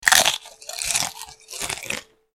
Soundeffekte hinzufügen
Jedes Mal, wenn der Spieler den Apfel berührt, soll ein Essgeräusch abgespielt werden.
eating_sound.ogg